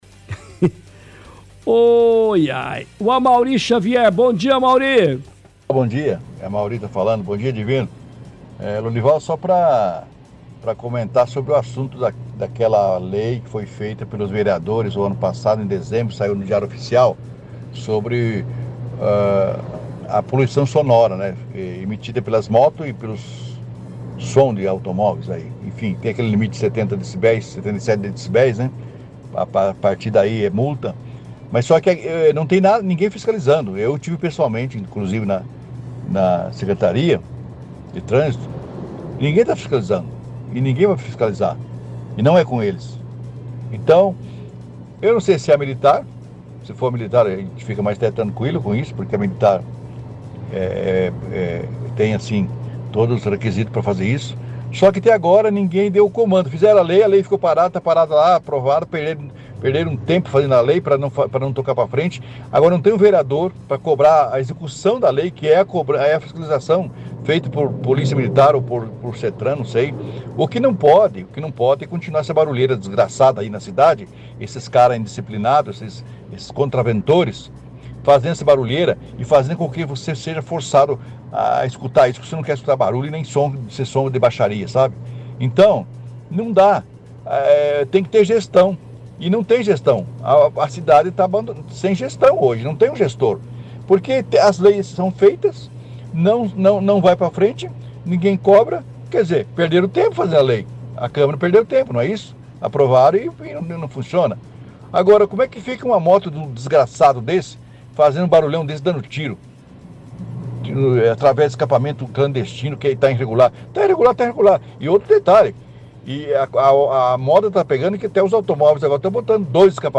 – Ouvinte questiona sobre a lei que foi publicada ano passado sobre barulho sonoro de moto, fala que já foi na secretaria trânsito e transporte, mas que não tem ninguém fiscalizando. Reclama que os barulhos na cidade com escapamento de motos estão cada dia mais altos. Questiona quem é o órgão responsável para fiscalização se o Settran ou PM.